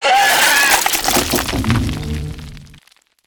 hole.ogg